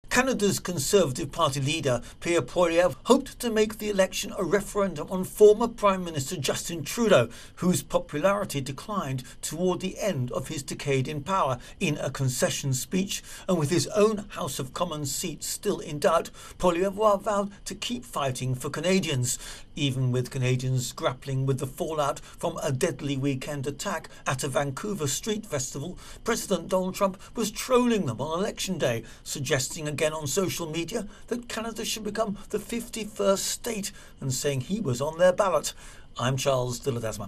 Mark Carney warns Canadians in Liberal Party victory speech: 'Trump is trying to break us'